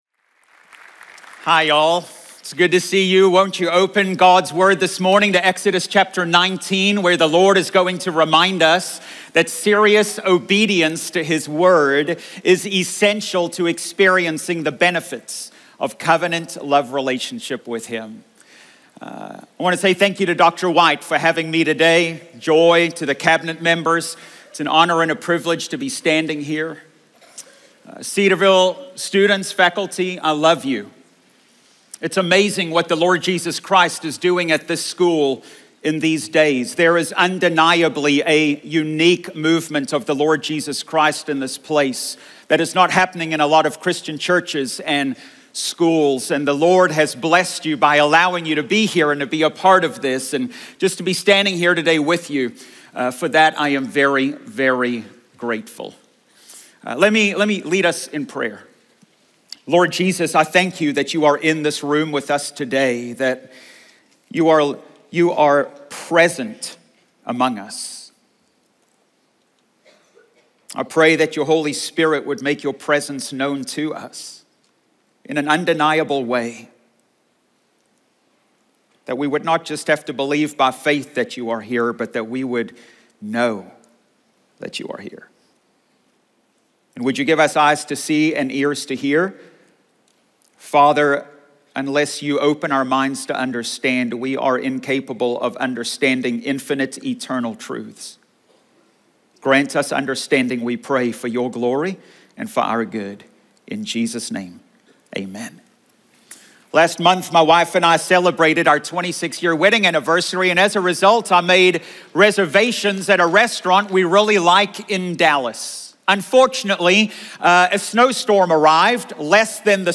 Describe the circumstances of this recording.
Cedarville University Chapel Message